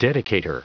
Prononciation du mot dedicator en anglais (fichier audio)
Prononciation du mot : dedicator